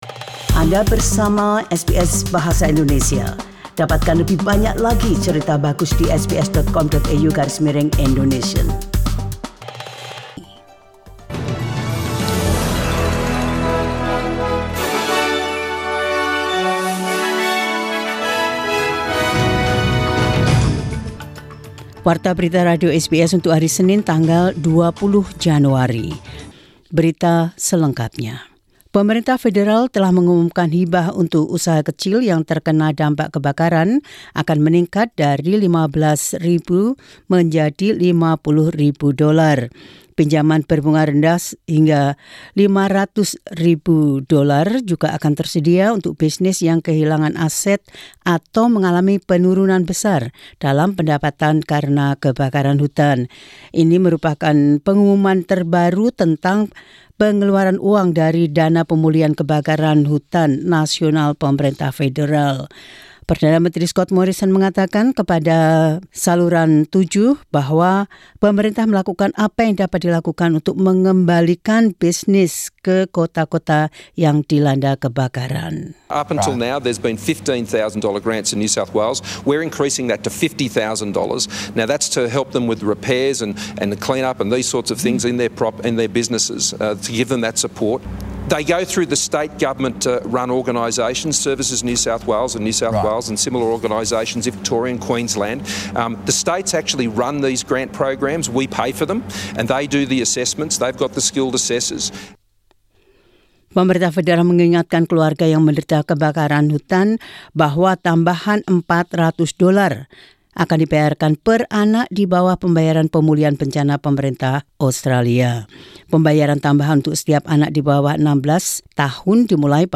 SBS Radio News in Indonesian 20 Jan 2020.